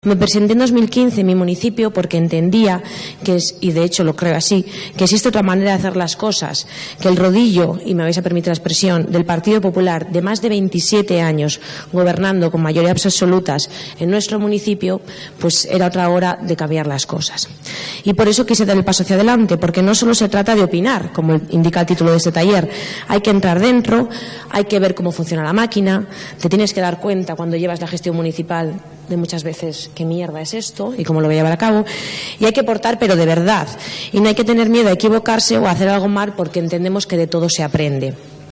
La alcaldesa de Haro, resumió así su experiencia sobre cómo accedió a la Alcaldía de Haro, dentro de su intervención en la mesa de debate `¿Opinar o gobernar?´ en la Escuela de Buen Gobierno del PSOE.